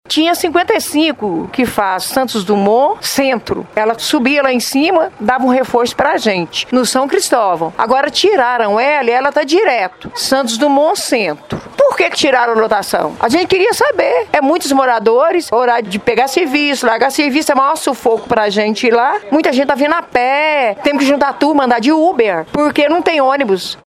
É o caso desta passageira, que prefere não ter a identidade revelada.
Moradora do São Cristóvão